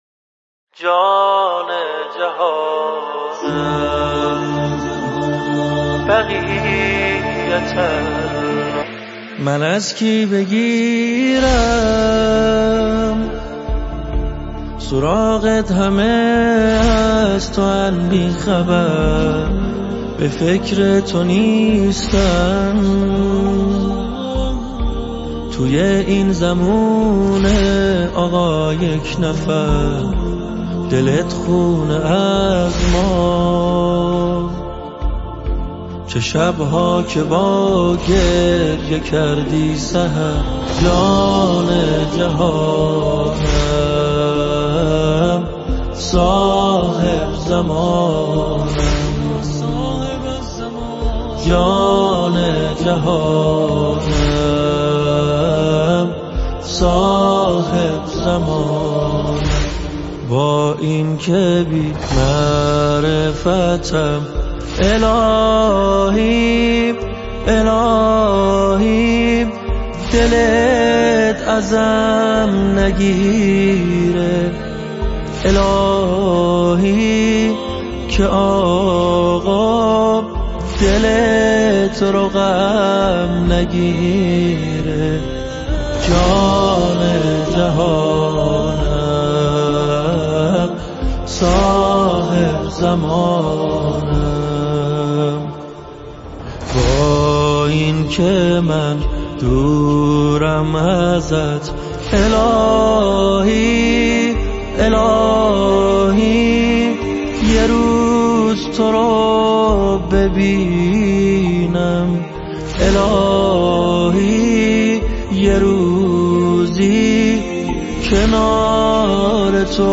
صوت اصلی